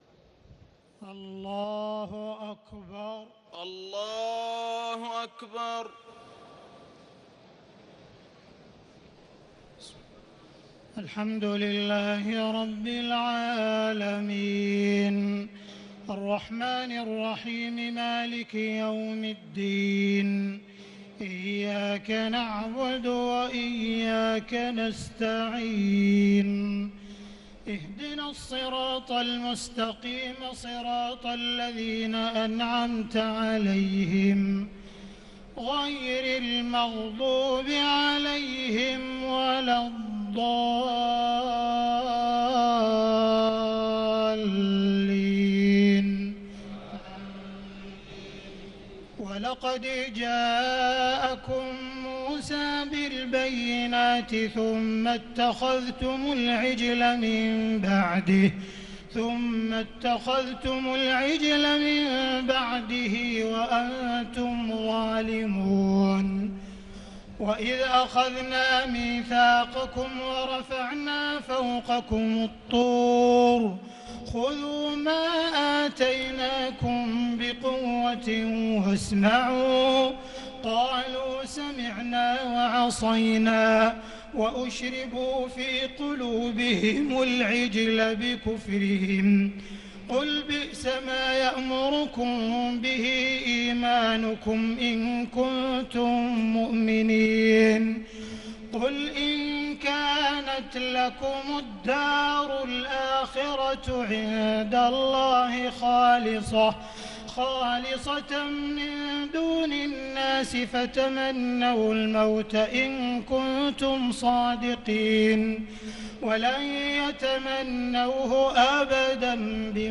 تهجد ليلة 21 رمضان 1438هـ من سورة البقرة (92-141) Tahajjud 21 st night Ramadan 1438H from Surah Al-Baqara > تراويح الحرم المكي عام 1438 🕋 > التراويح - تلاوات الحرمين